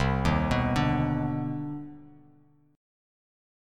CmM9 Chord
Listen to CmM9 strummed